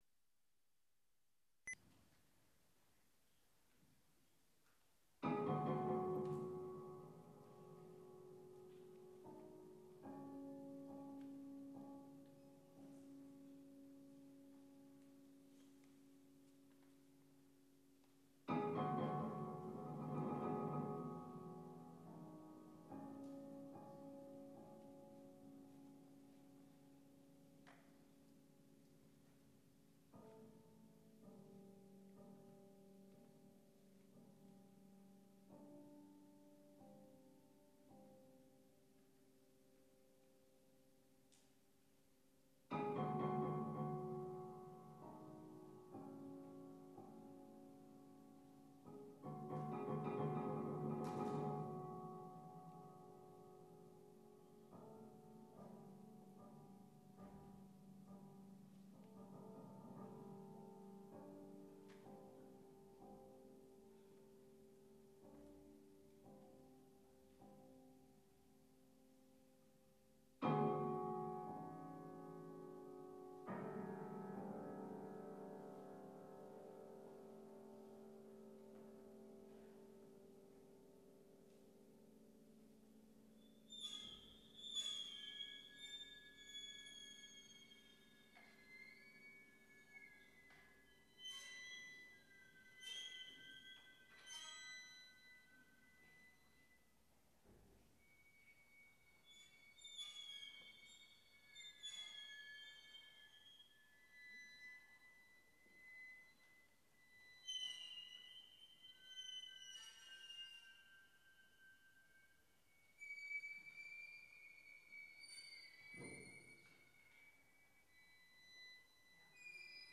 pour flûte en sol, clarinette, violon et piano